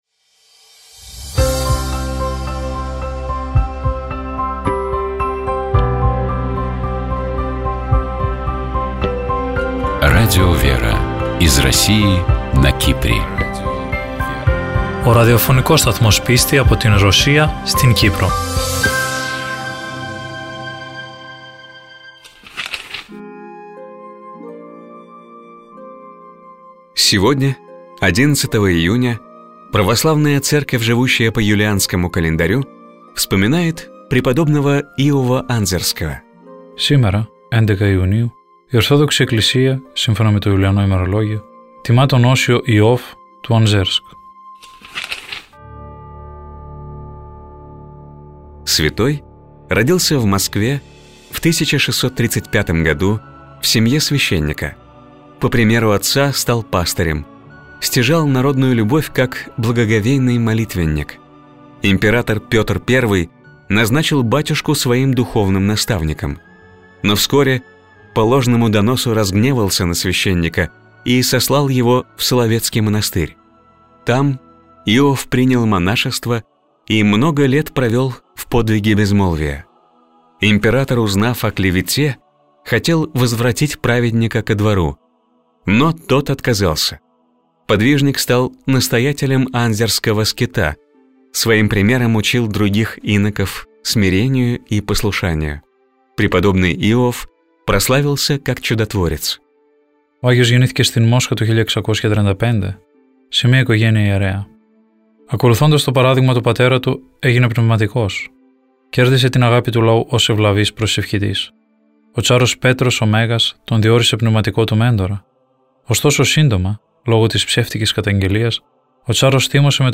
По благословению митрополита Лимассольского Афанасия (Кипрская Православная Церковь) в эфире радио Лимассольской митрополии начали выходить программы Радио ВЕРА. Популярные у российского слушателя программы переводятся на греческий язык и озвучиваются в студии Радио ВЕРА: «Православный календарь», «Евангелие день за днем», «Мудрость святой Руси», «ПроСтранствия», «Частное мнение» и другие.